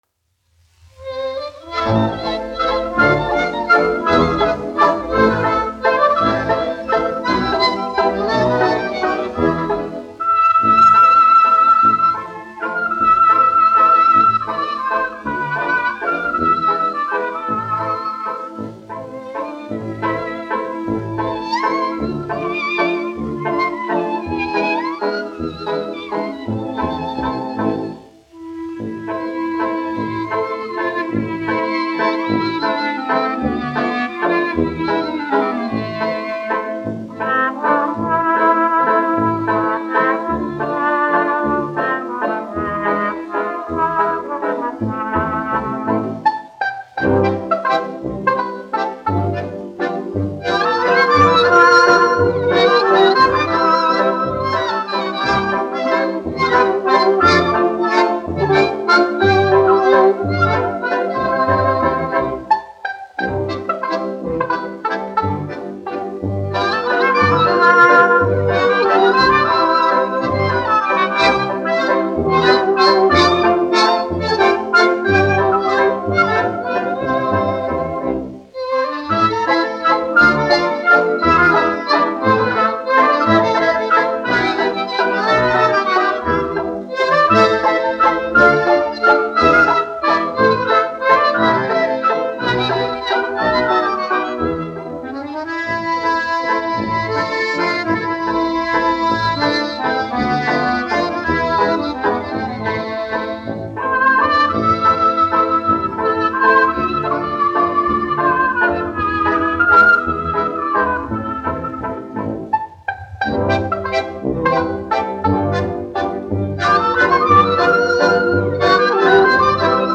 1 skpl. : analogs, 78 apgr/min, mono ; 25 cm
Deju orķestra mūzika
Skaņuplate